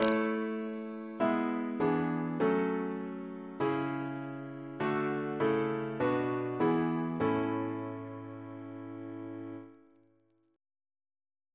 CCP: Chant sampler
Single chant in A Composer: Henry Smart (1813-1879) Reference psalters: OCB: 80; PP/SNCB: 7